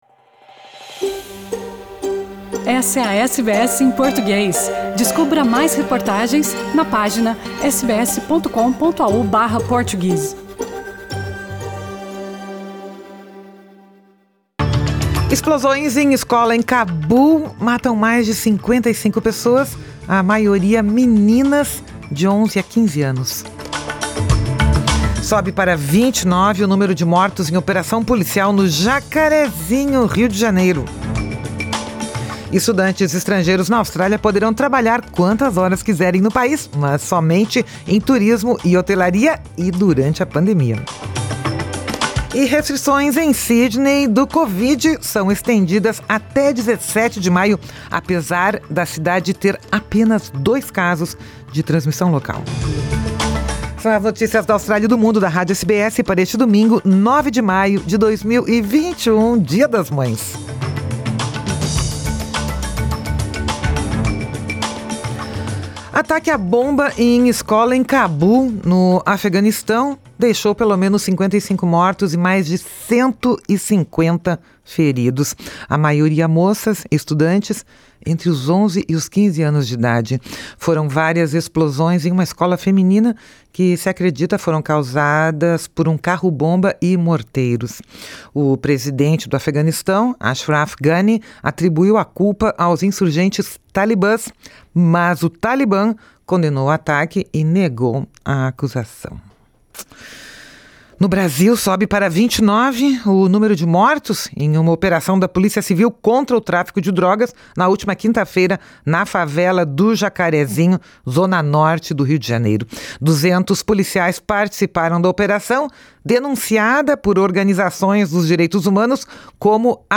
São as notícias da Austrália e do Mundo da Rádio SBS para este domingo, 9 de maio de 2021 - Dia das Mães